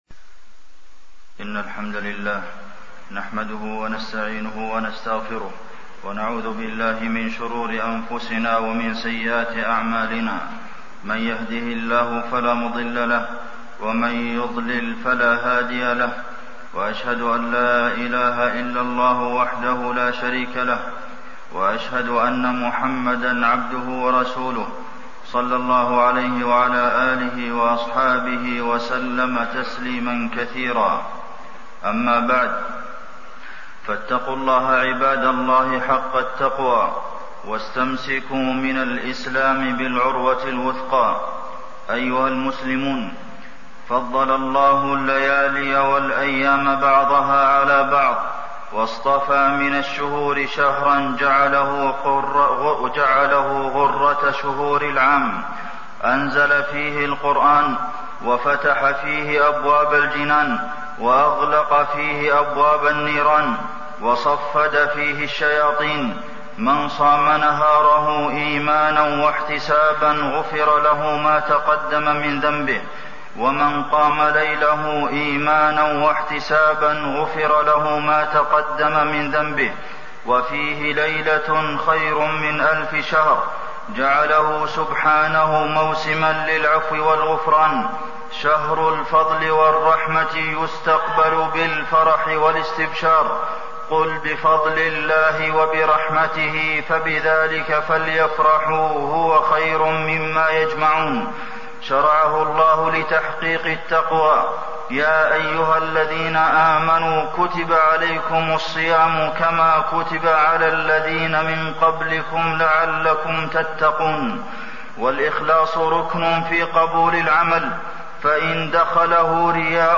تاريخ النشر ٣ رمضان ١٤٣١ هـ المكان: المسجد النبوي الشيخ: فضيلة الشيخ د. عبدالمحسن بن محمد القاسم فضيلة الشيخ د. عبدالمحسن بن محمد القاسم خير الشهور رمضان